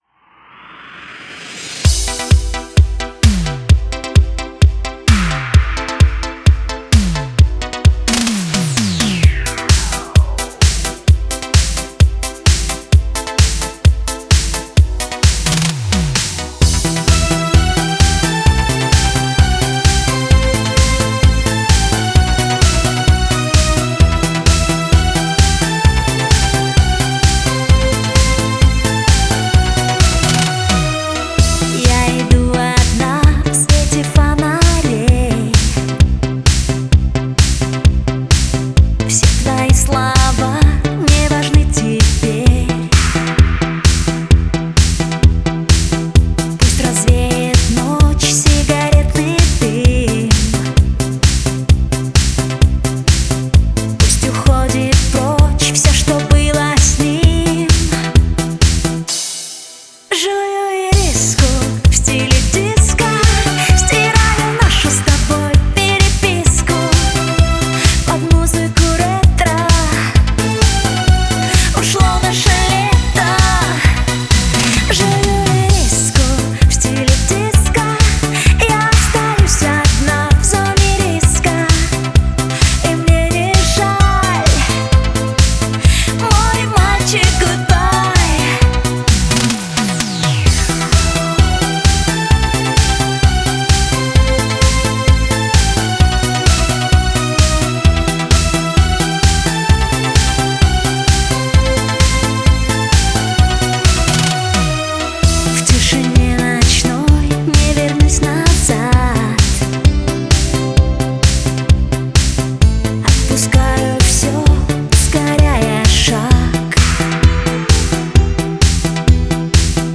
vis_v_stile_disko.mp3